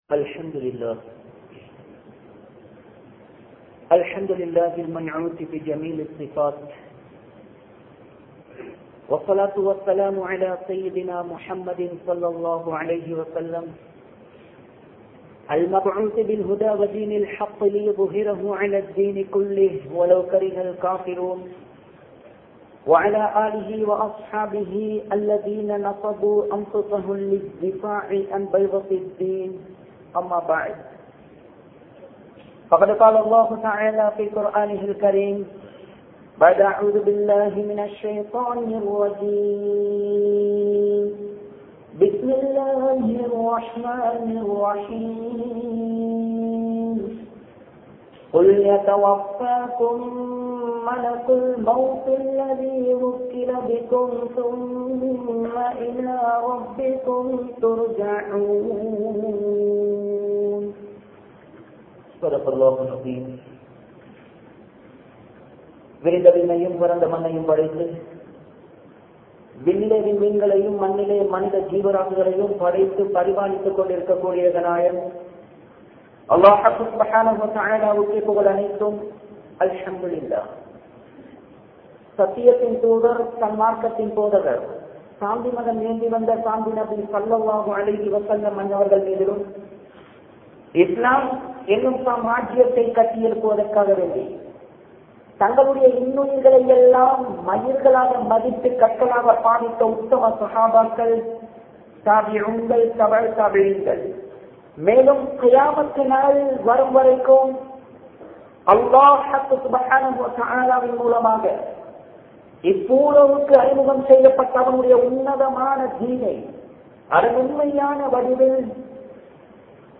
Marakka Koodaatha Maranam (மறக்க கூடாத மரணம்) | Audio Bayans | All Ceylon Muslim Youth Community | Addalaichenai
Majmaulkareeb Jumuah Masjith